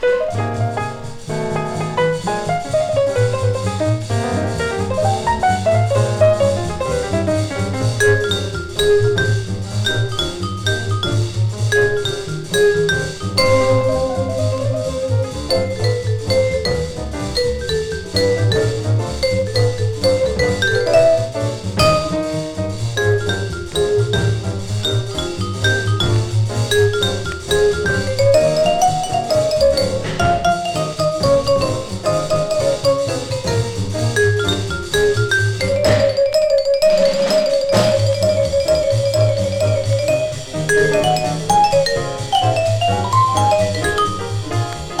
Jazz　USA　10inchレコード　33rpm　Mono